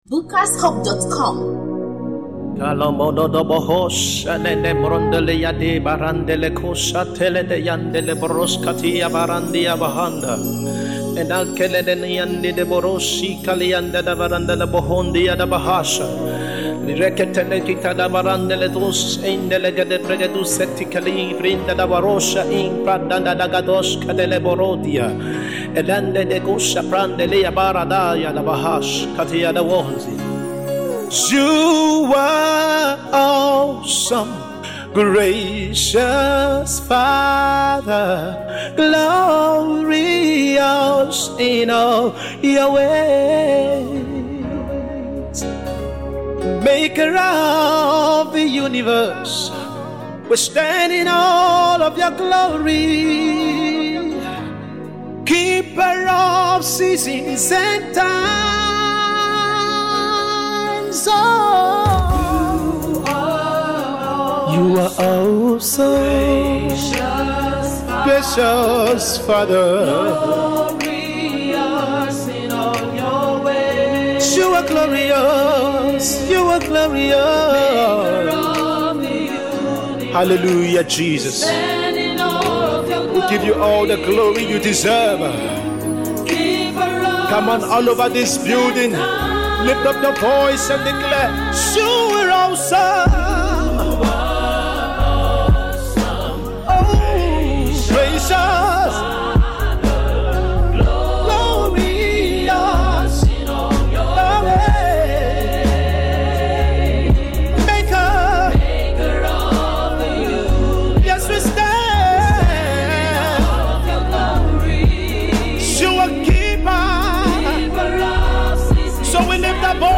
gospel
worship masterpiece